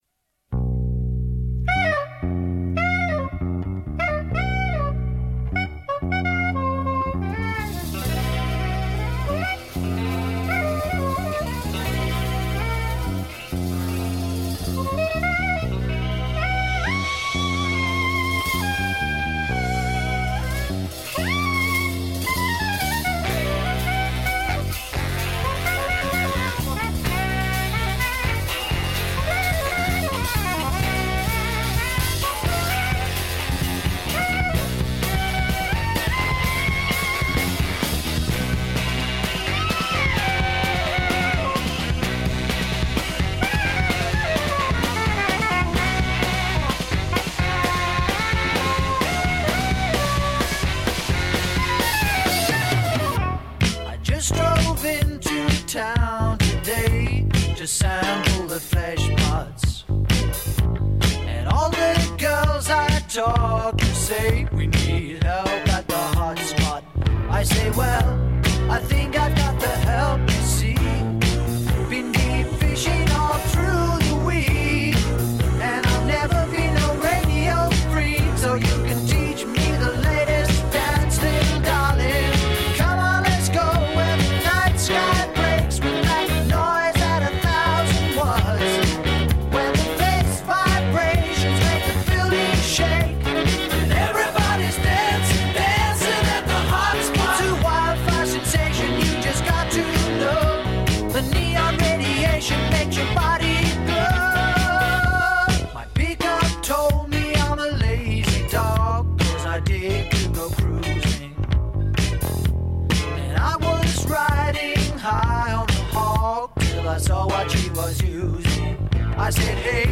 The production is too organic to be Horn or SAW, I think.